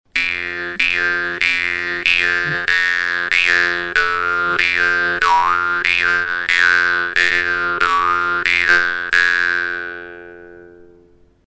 Die Wimmer-Bades Pro erinnert von der Form her an die sehr einfachen Modelle aus der gleichen Werkstatt, ihr Klang ist allerdings klar und die Stimmung sauber.
Ihre relativ straffe Zunge erzeugt einen lauten, scharfen Sound, der auch auf der Bühne mithalten kann.